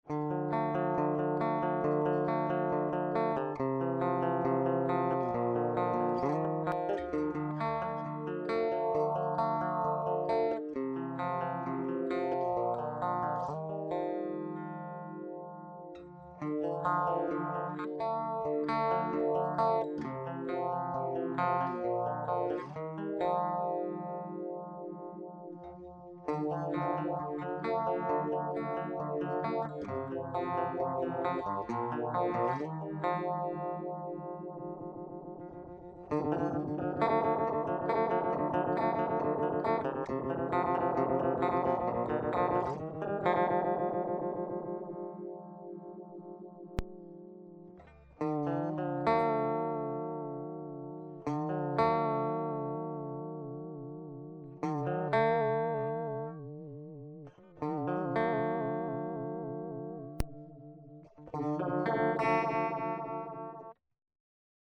LE SON DE LA SMALL STONE :
Enregistrer directement dans une table yamaha MG-12/4, avec une carte ECHO MIA MIDI.
Le son est neutre,sans traitement.
COLOR OFF ,VIBRATO MOD à la fin :